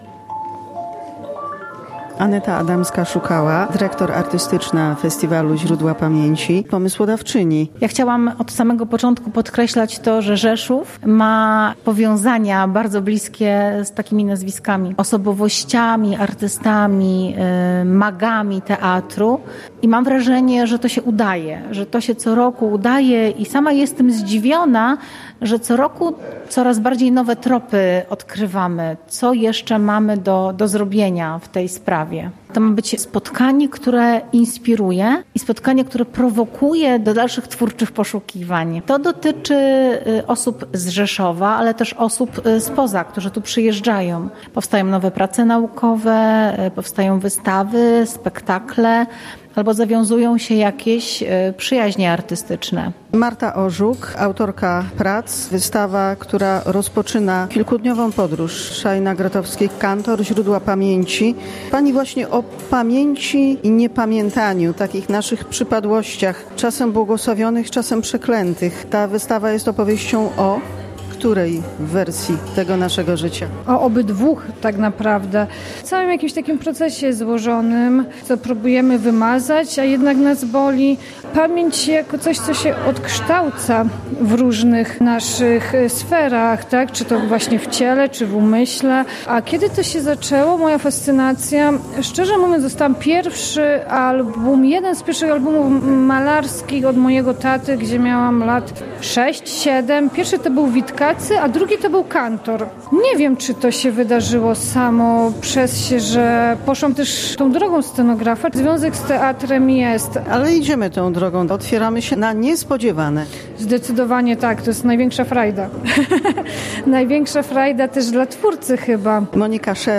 Relację z wydarzenia